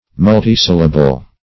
Search Result for " multisyllable" : The Collaborative International Dictionary of English v.0.48: Multisyllable \Mul"ti*syl`la*ble\, n. [Multi- + syllable.] A word of many syllables; a polysyllable.